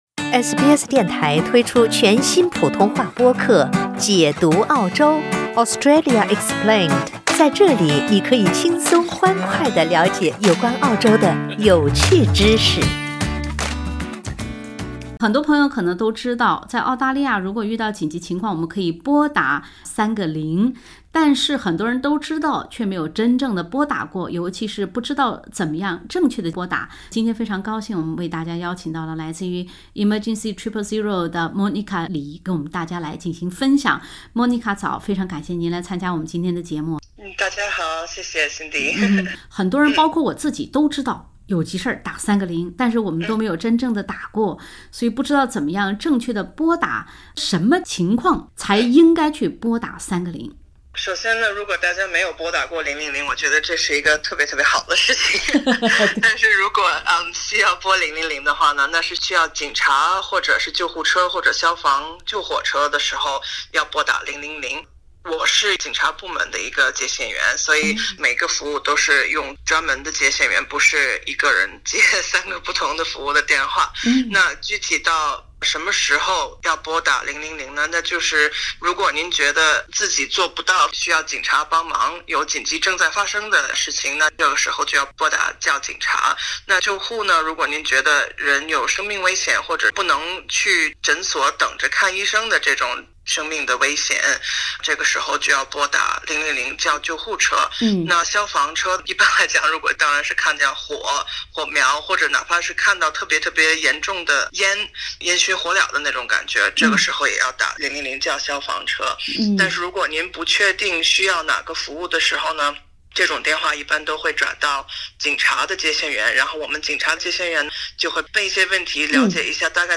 场模拟语音演示